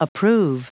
Prononciation du mot approve en anglais (fichier audio)
Prononciation du mot : approve